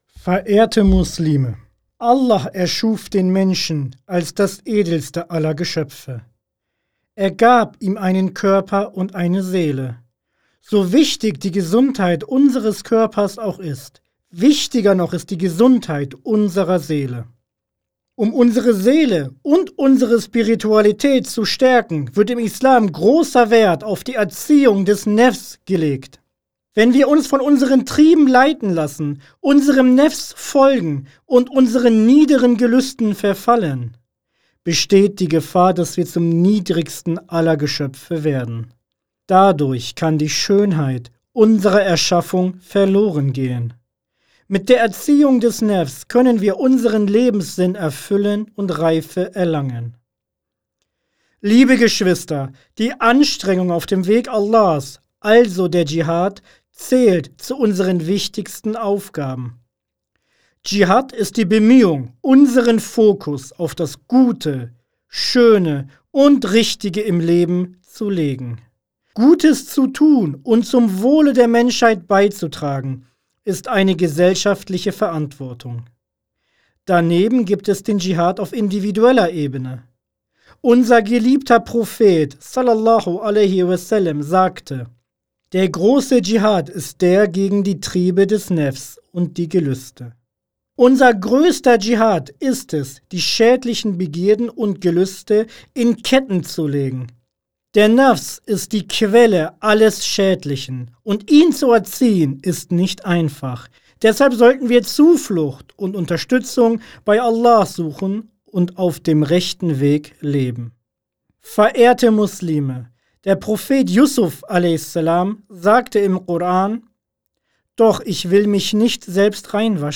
IGMG Freitagspredigten